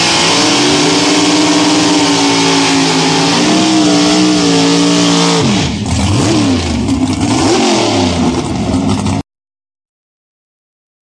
1969 Dodge Dart - 604 B1 Dodge
Click on the image below to see an actual movie clip from the races
burnout.rm